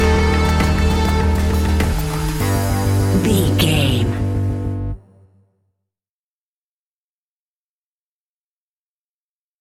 Aeolian/Minor
scary
ominous
dark
disturbing
eerie
synthesiser
percussion
drums
bass guitar
strings
electronic music